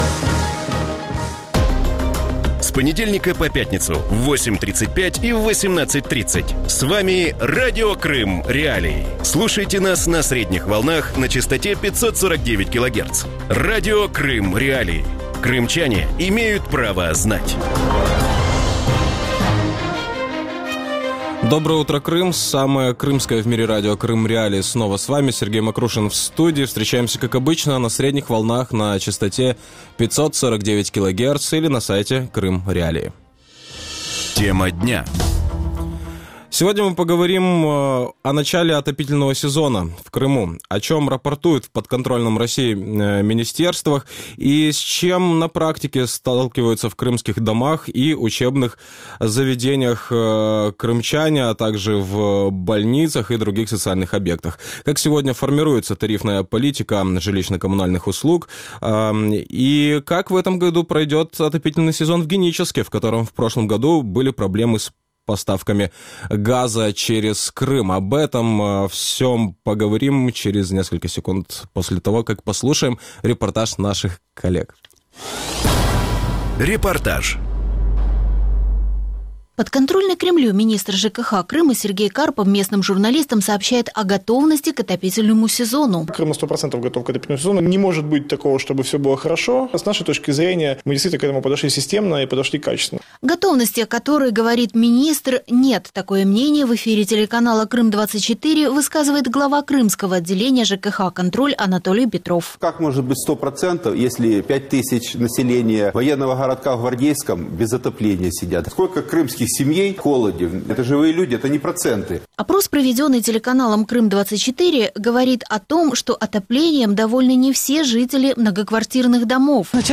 Вранці в ефірі Радіо Крим.Реалії говорять про початок опалювального сезону на півострові.